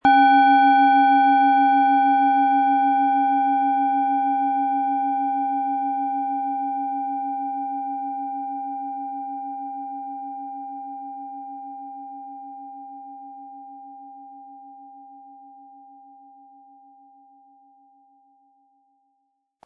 Planetenton 1
Unter dem Artikel-Bild finden Sie den Original-Klang dieser Schale im Audio-Player - Jetzt reinhören.
SchalenformBihar
MaterialBronze